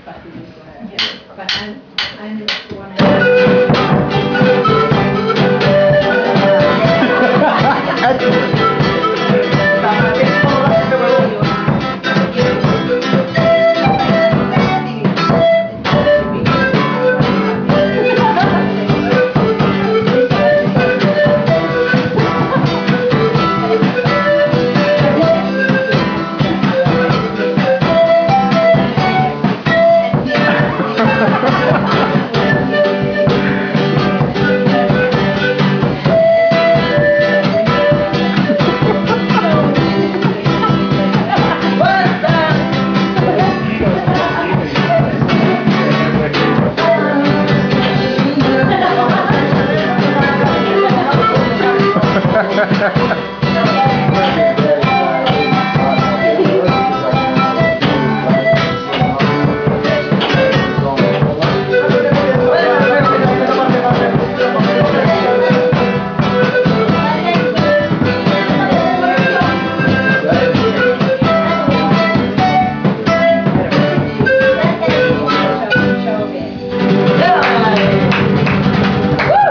drums
pan flute
live with their instruments